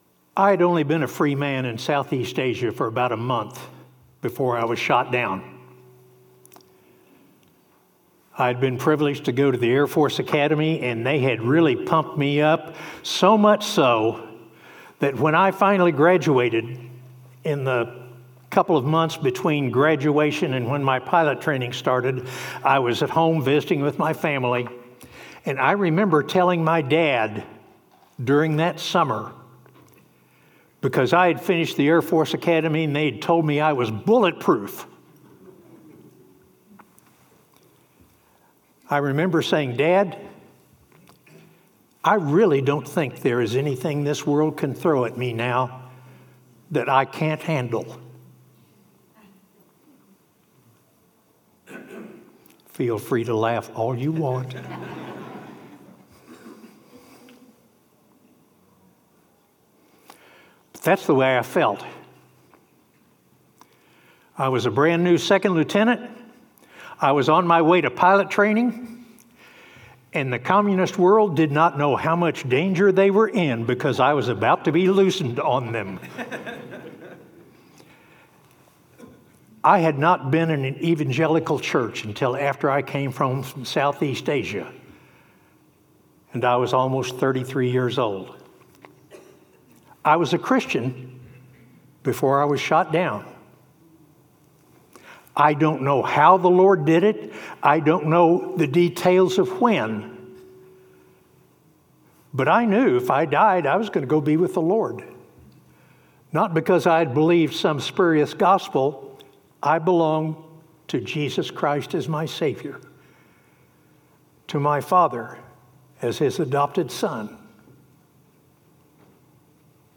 Veterans Day Banquet 2025 Keynote Address